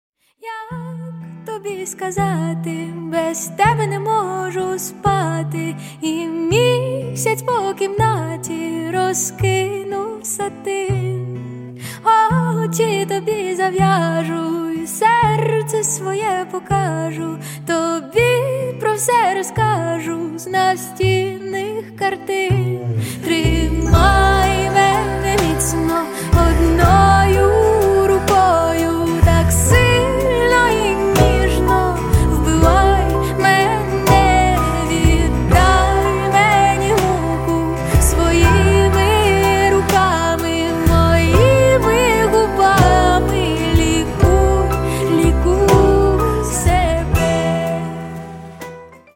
поп
гитара
мелодичные
спокойные
пианино
нежные